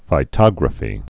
(fī-tŏgrə-fē)